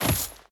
Footsteps